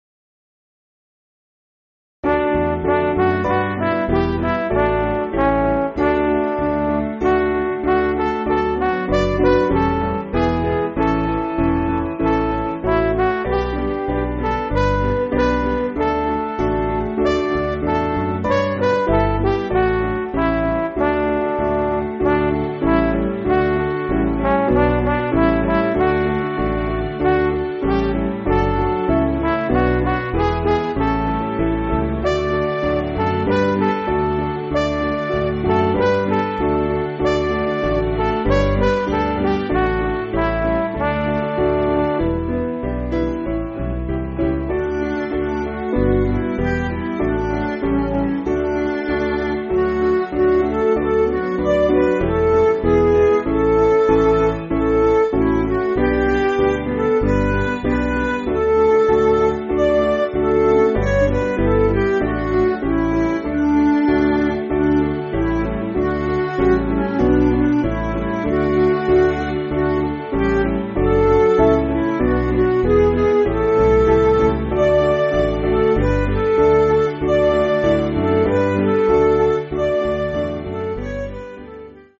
7.7.7.7. with refrain
Piano & Instrumental